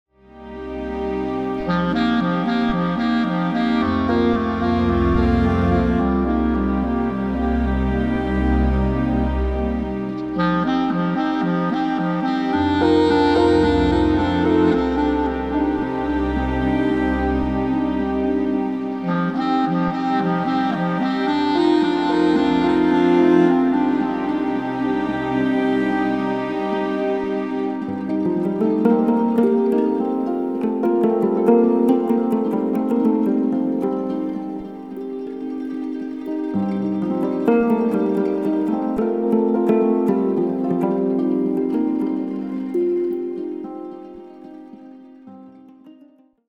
Fantasy